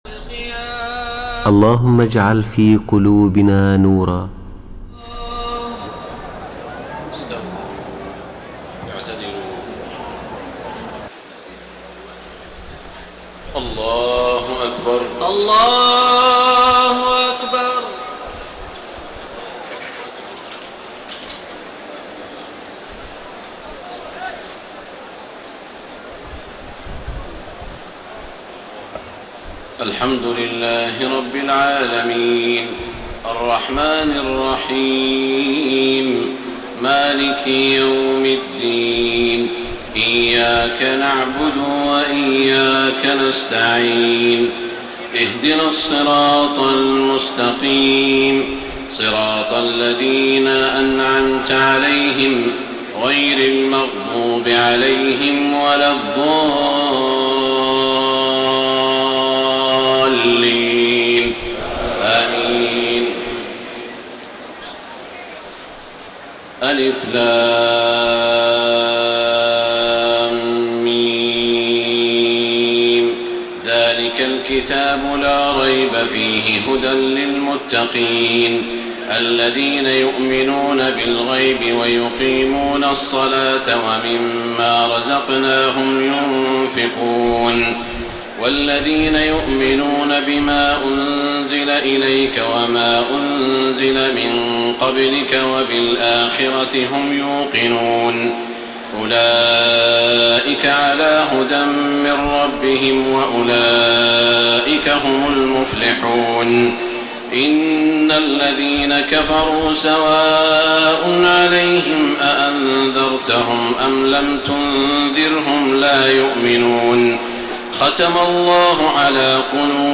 تهجد ليلة 21 رمضان 1433هـ من سورة البقرة (1-91) Tahajjud 21 st night Ramadan 1433H from Surah Al-Baqara > تراويح الحرم المكي عام 1433 🕋 > التراويح - تلاوات الحرمين